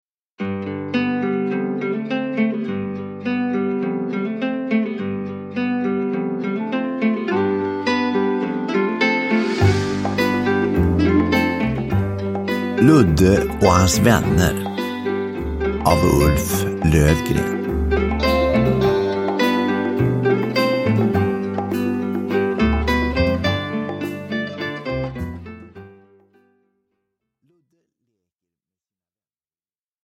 Ludde och hans vänner – Ljudbok – Laddas ner